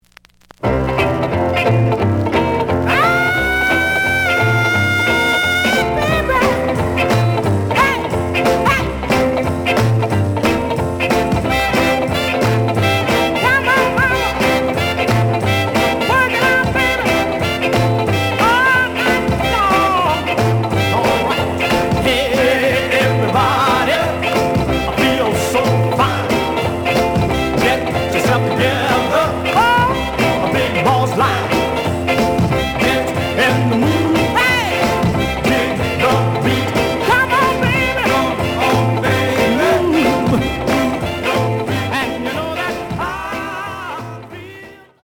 The audio sample is recorded from the actual item.
●Genre: Soul, 60's Soul
Some damage on both side labels. Plays good.)